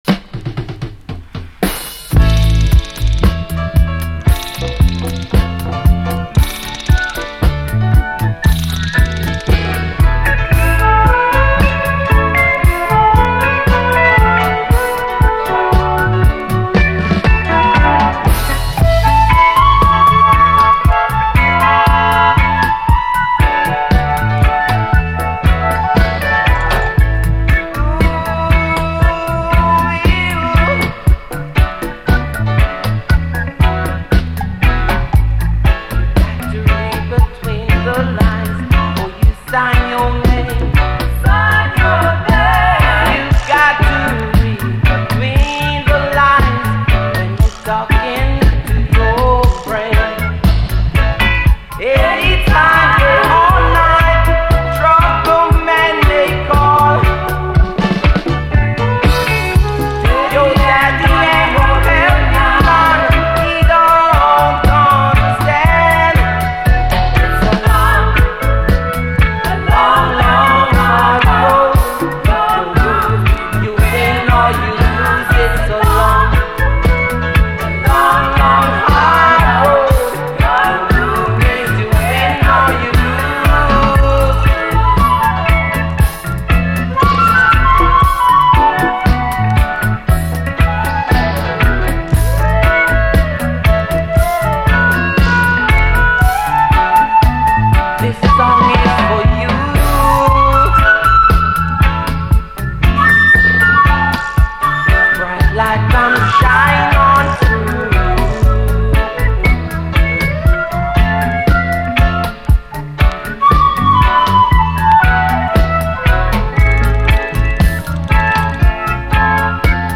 REGGAE
泣きの哀愁UKルーツ・レゲエ
哀愁系ルーツ〜初期レゲエなサウンドでちょっと洗練された感じもあり。